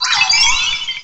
cry_not_azelf.aif